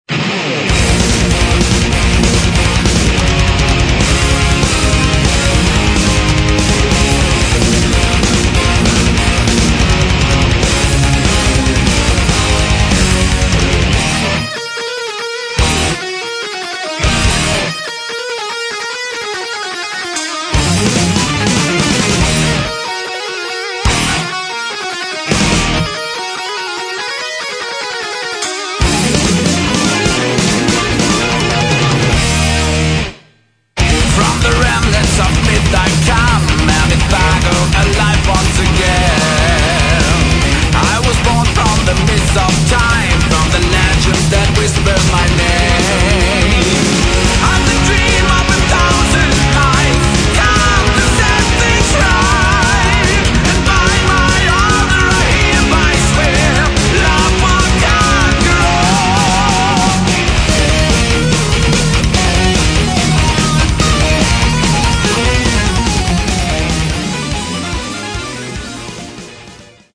Metal
вокал
барабаны
клавиши
гитара
бас